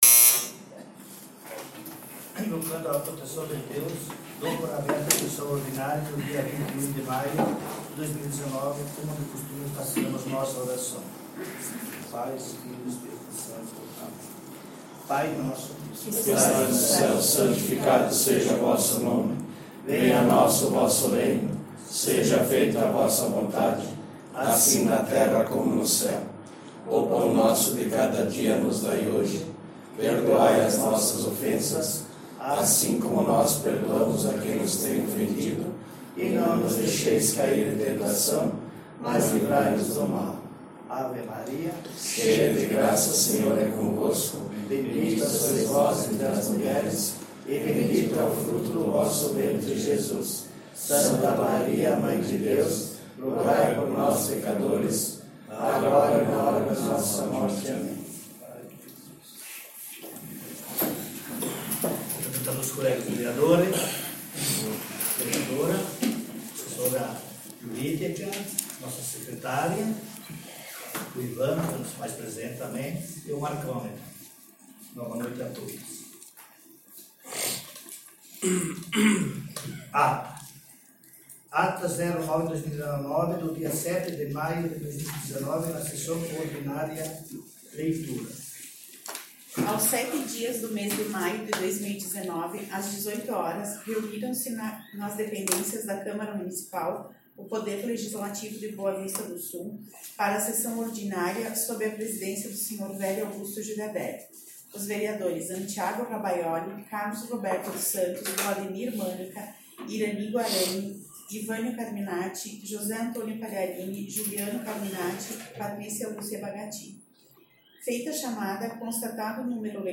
Sessão Ordinária dia 21/05/19